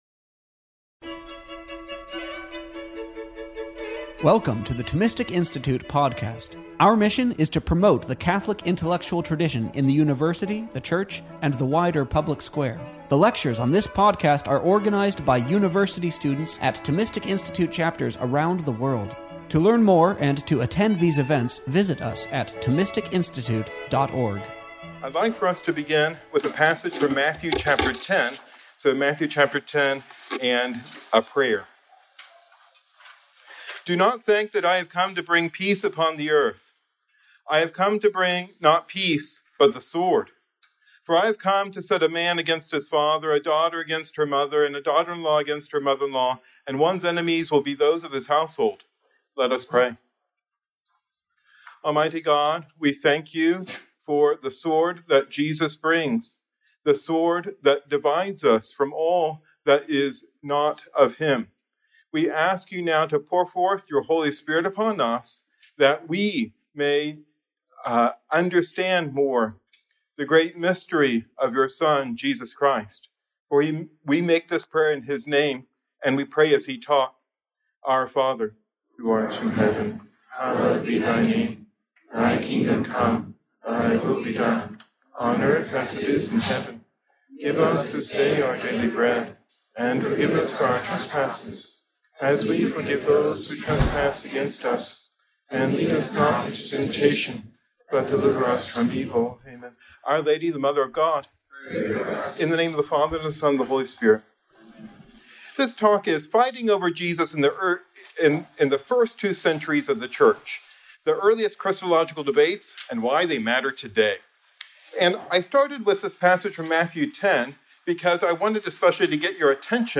This lecture was given on February 16th, 2024…